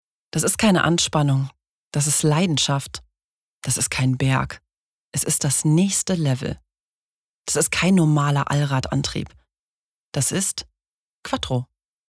sehr variabel
Commercial (Werbung)